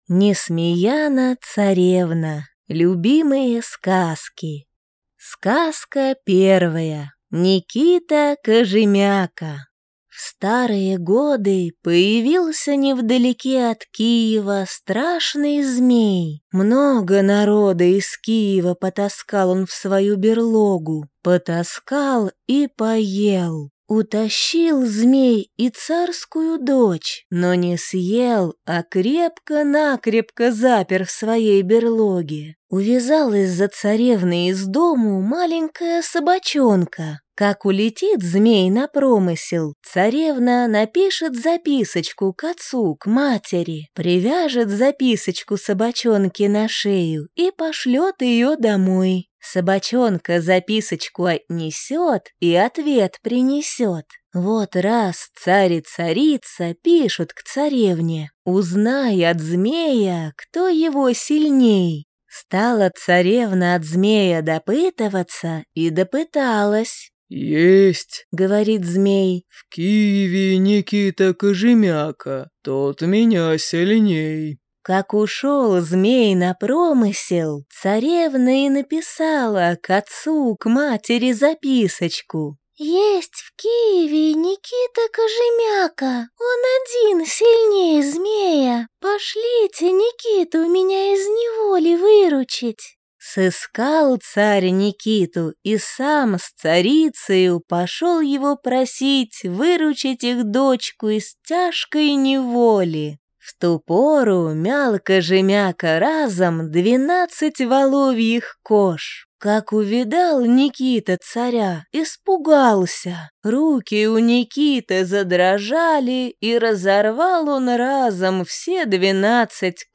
Аудиокнига Несмеяна-царевна.